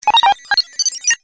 标签： 放松 思考
声道立体声